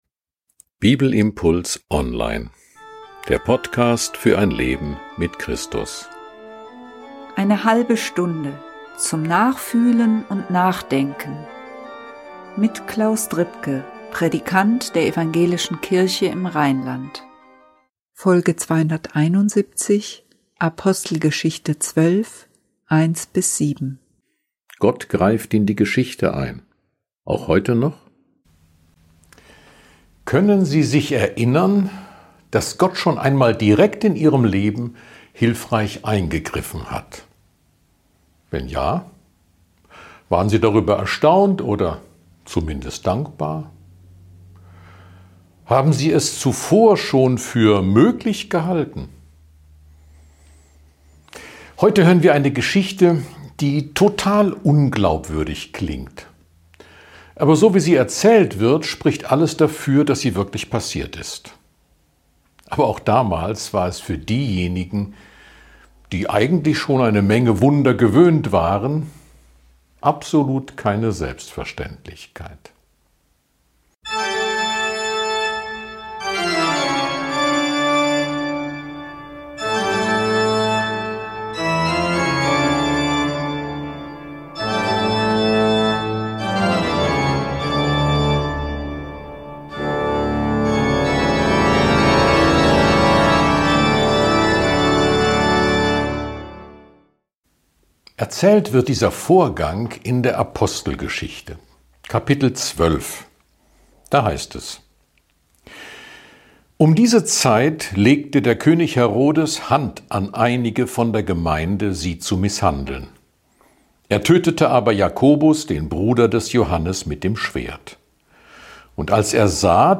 Eigentlich kann man so nur fragen, wenn Gott jederzeit in der Welt eingreifen könnte. Und was ist, wenn er das in Wirklichkeit ständig macht? Ein Bibelimpuls zu Apostelgeschichte 12, 1-7.